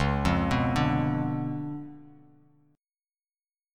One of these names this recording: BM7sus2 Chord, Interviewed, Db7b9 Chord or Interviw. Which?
Db7b9 Chord